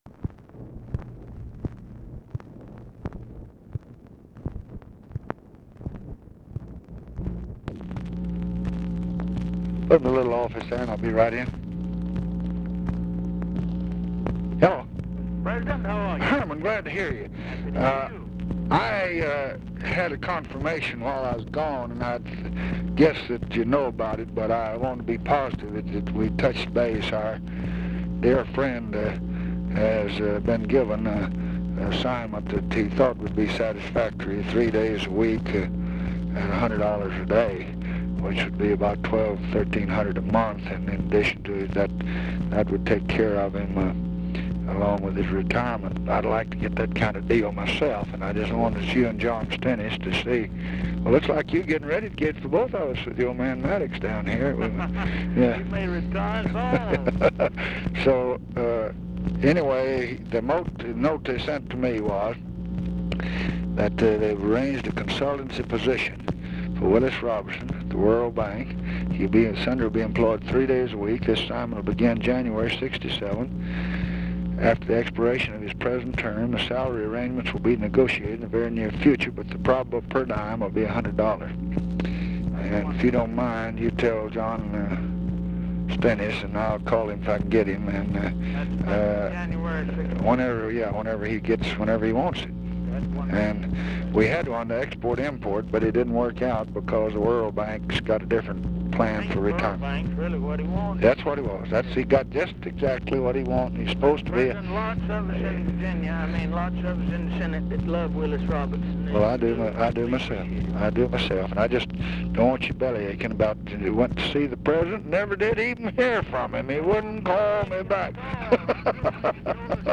Conversation with HERMAN TALMADGE and OFFICE CONVERSATION, October 11, 1966
Secret White House Tapes